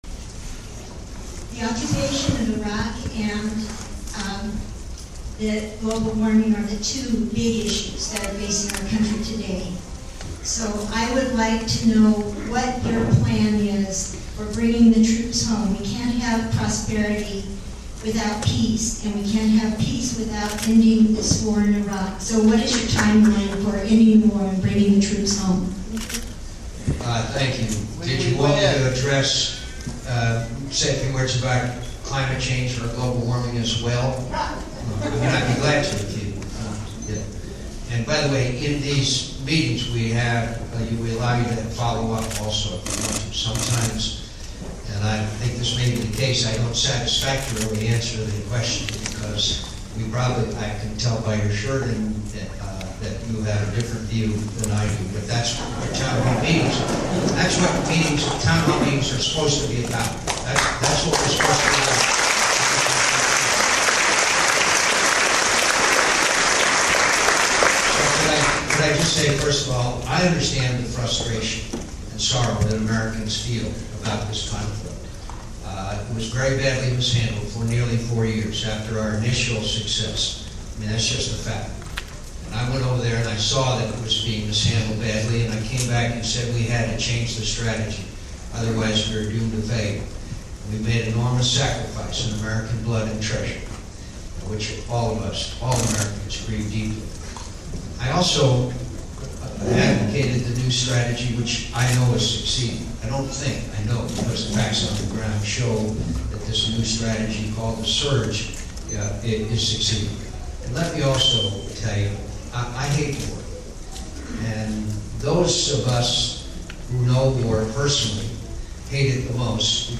While here, he held a Town Hall meeting at the university and then headed downtown for a fund raiser at the Radisson Hotel.